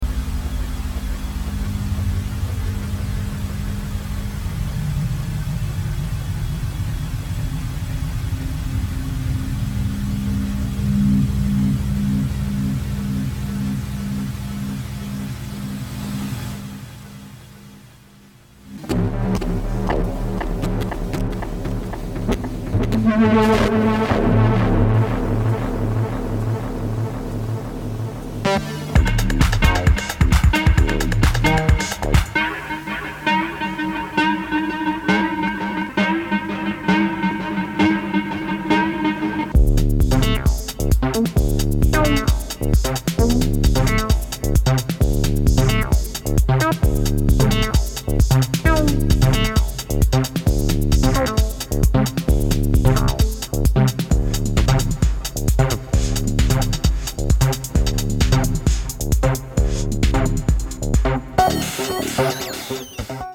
Nada polido, mas impoluto.